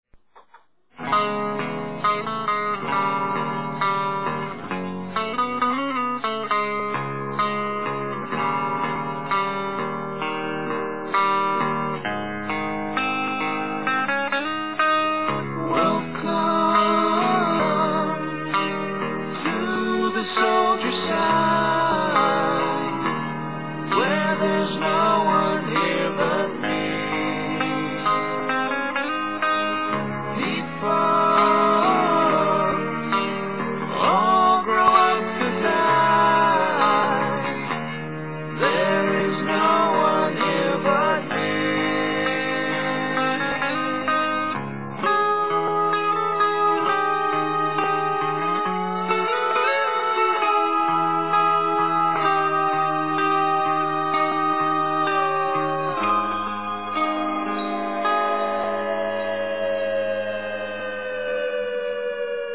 Список файлов рубрики Альтернативный рок